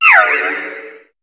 Cri de Sucroquin dans Pokémon HOME.